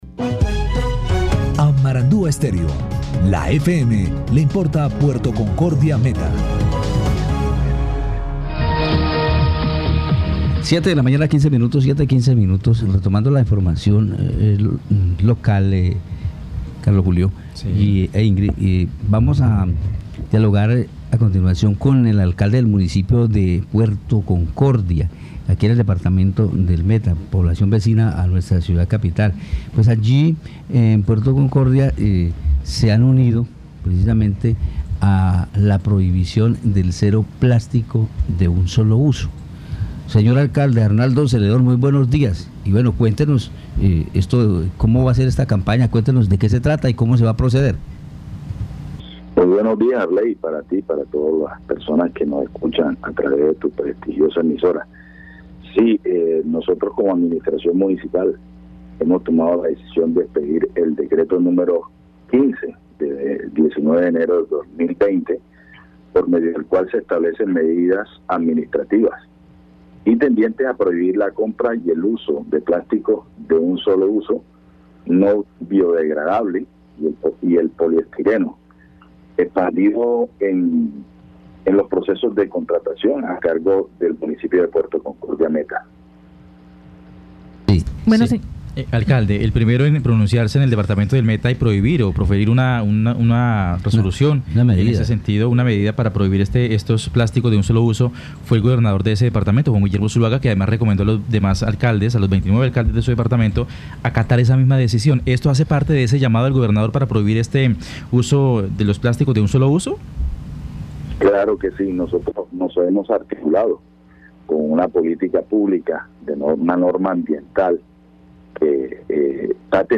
Escuche a Arnaldo Celedón, alcalde de Puerto Concordia, Meta.